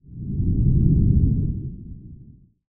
shooterAnonStep.ogg